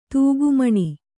♪ tūgu maṇi